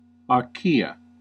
ar-KEE) is a domain of organisms.
En-us-Archaea.ogg.mp3